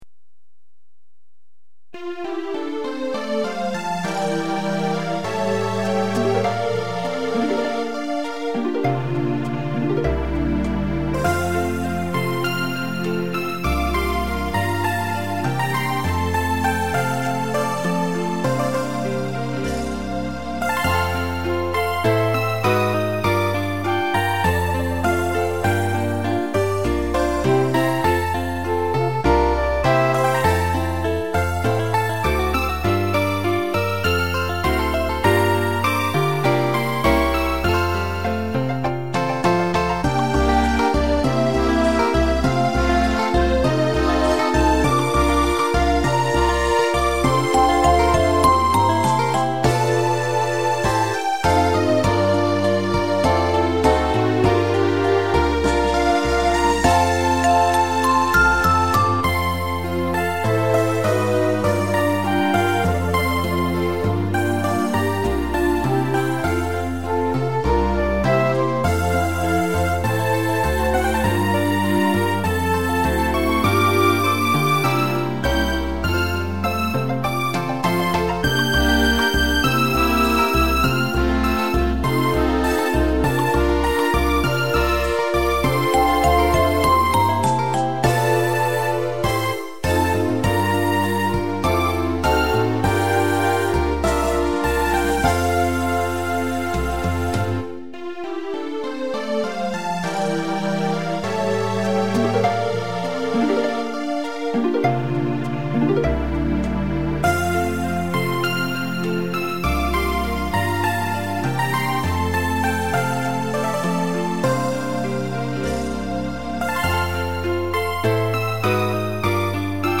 アンサンブル